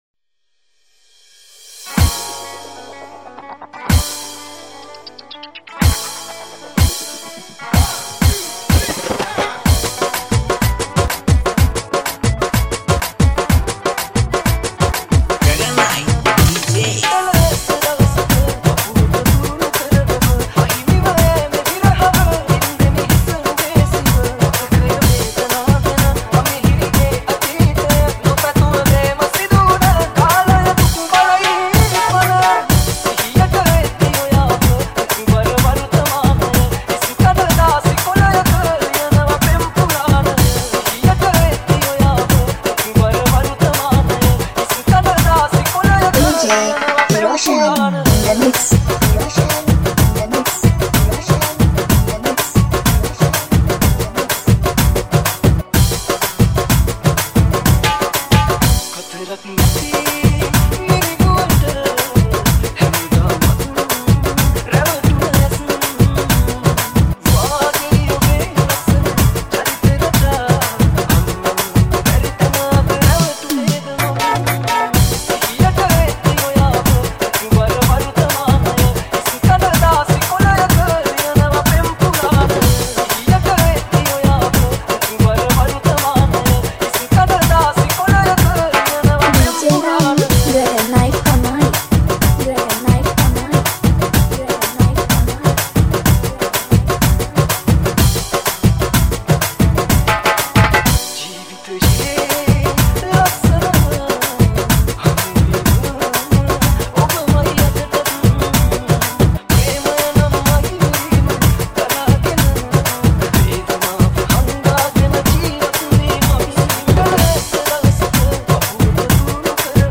Remix